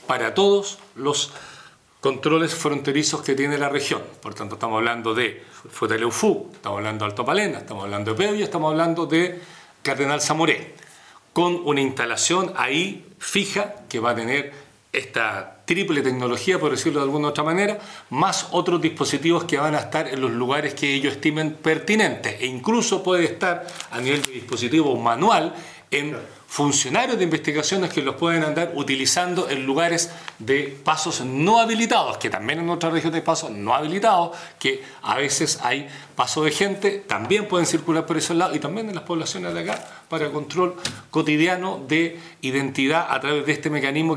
El Gobernador Vallespin indicó que este sistema se implementaría en los pasos fronterizos de Futaleufú, Pérez Rosales, Río Encuentro y Cardenal Antonio Samoré.